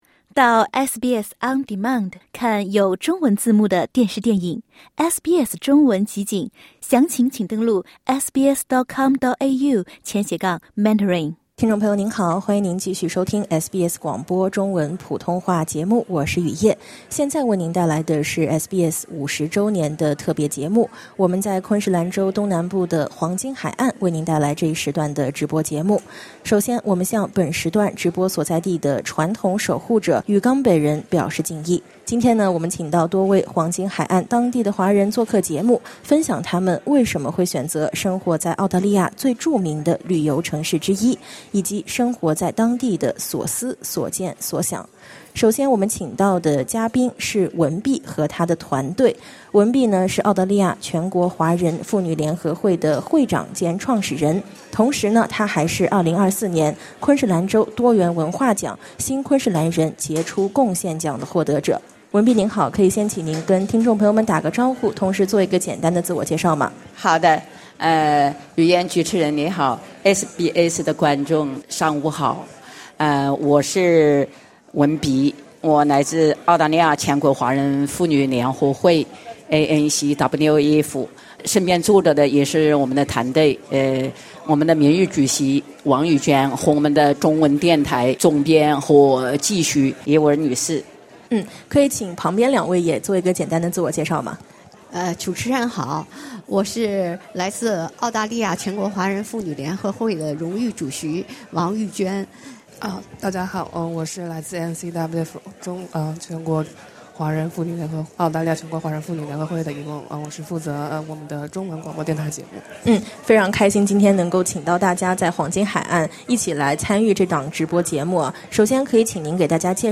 为庆祝SBS建台50周年，SBS普通话节目走出悉尼和墨尔本的演播室，来到昆士兰州东南部的黄金海岸，为听友带来一场特别的现场直播。